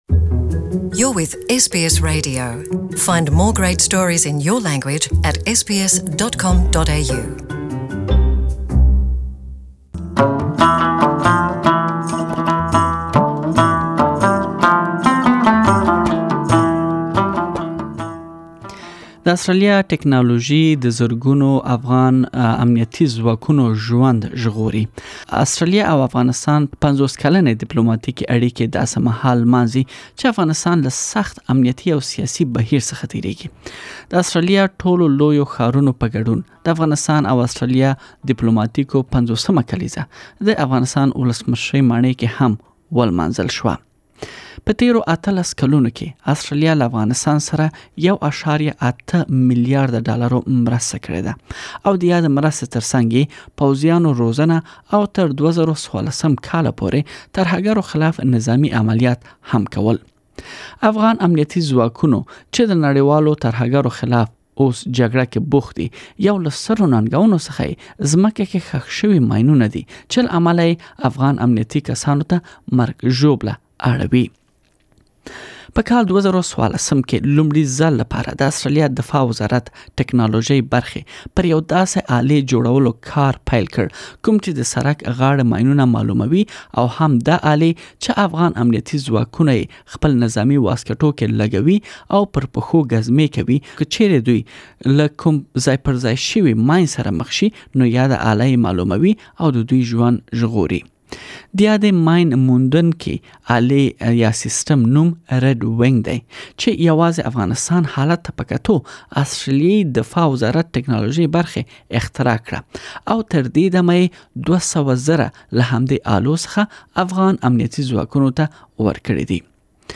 Afghanistan and Australia are celebrating their 50th year of diplomatic relations. Please listen to the full report in Pashto.